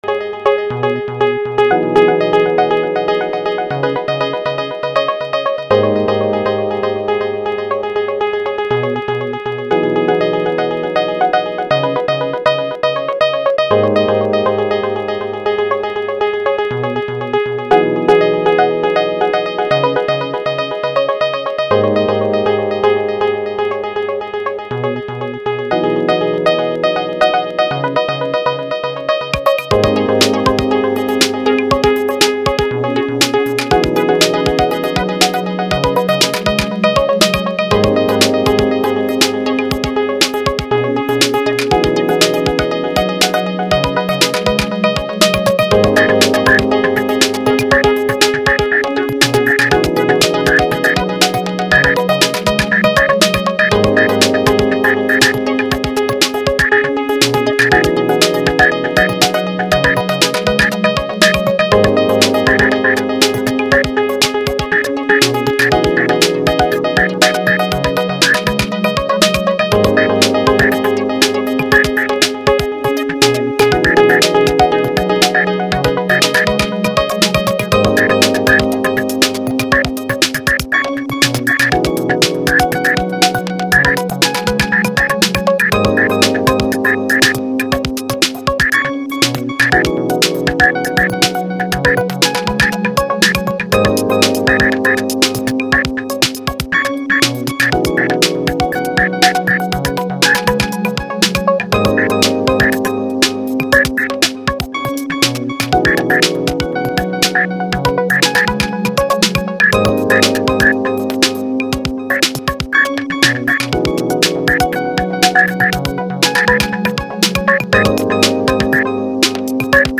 80's electric bass